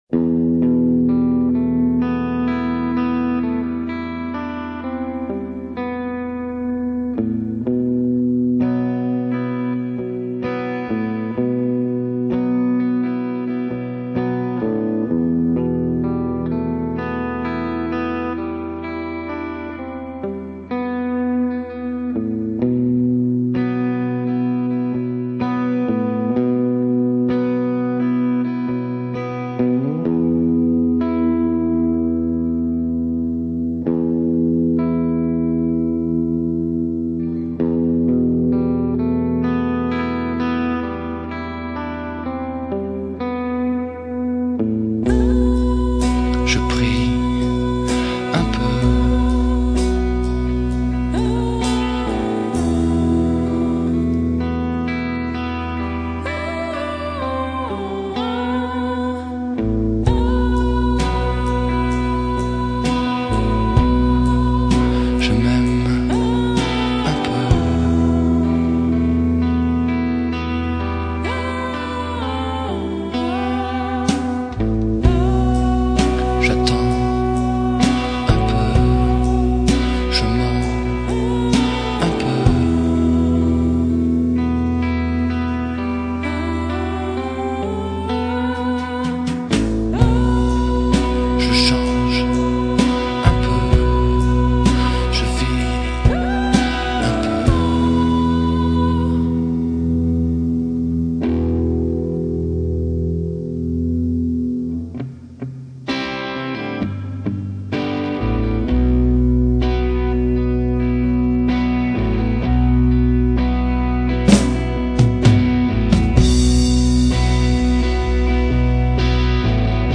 Sans machine, rien que des guitares.
À mesure que le film avance les guitares sont électriques.